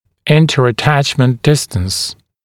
[ˌɪntərə’tæʧmənt ‘dɪstəns] [ˌинтэрэ’тэчмэнт ‘дистэнс]расстояние между аттачменами (в т.ч. между брекетами)